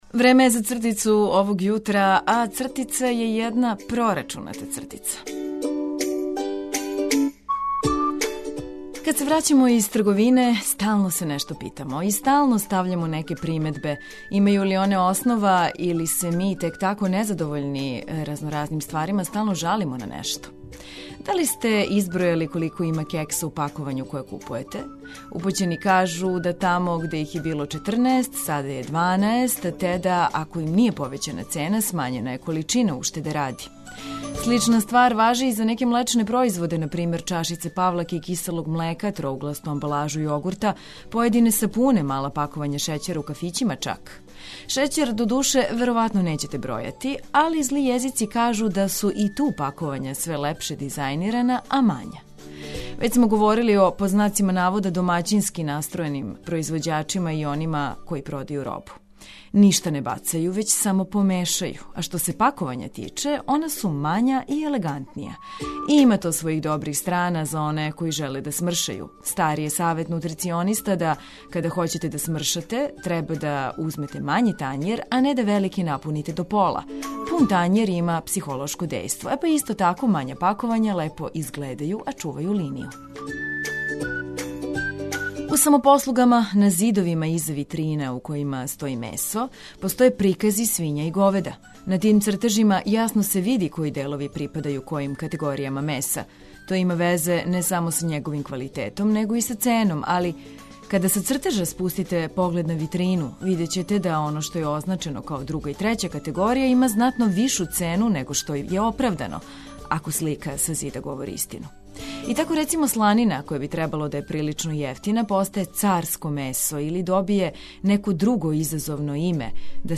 Разбуђивање сјајном музиком и информацијама које олакшавају живот.
У нашем друштву ћете сазнати све што је корисно будећи се уз ведру музику.